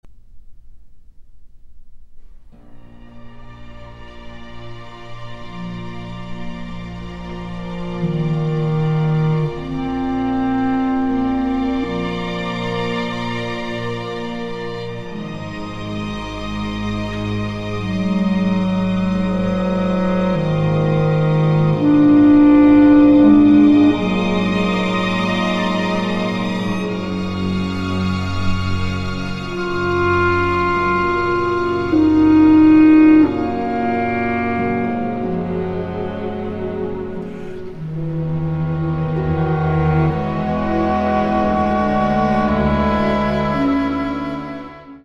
Larghetto (8:34)